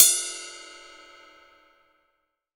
Index of /90_sSampleCDs/AKAI S6000 CD-ROM - Volume 3/Ride_Cymbal1/20INCH_ZIL_RIDE